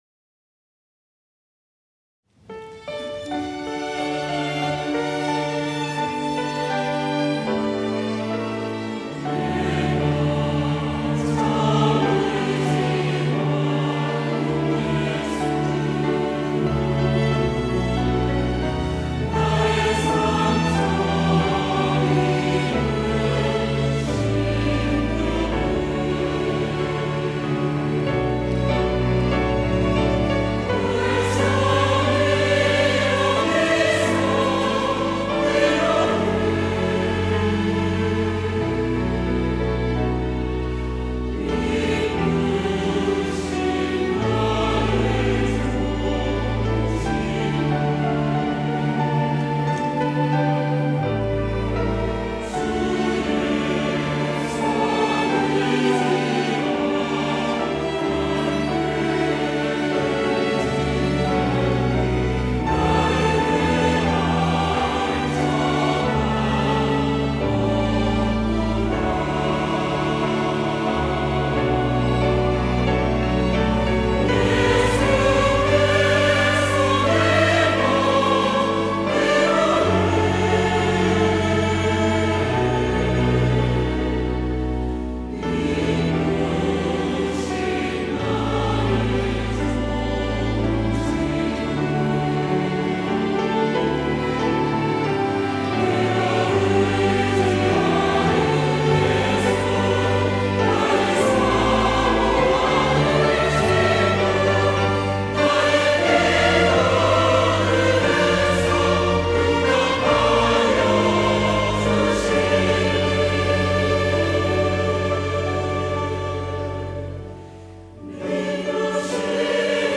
호산나(주일3부) - 내가 참 의지하는 예수
찬양대